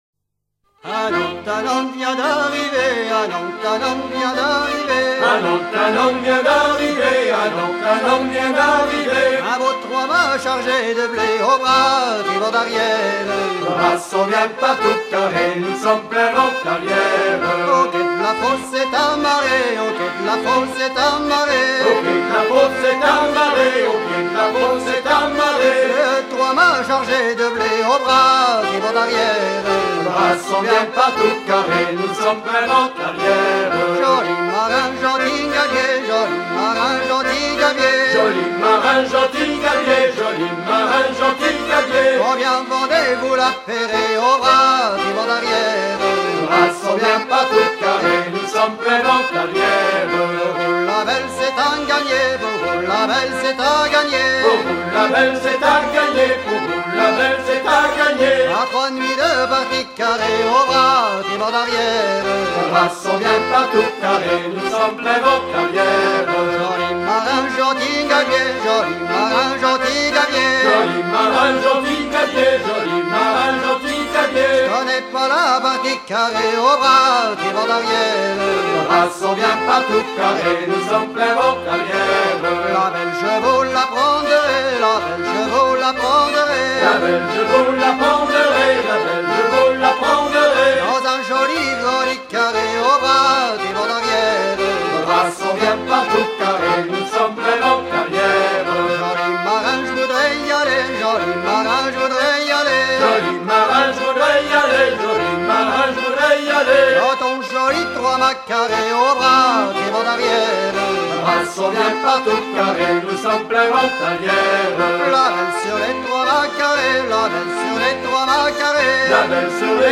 circonstance : maritimes
Genre laisse
Pièce musicale éditée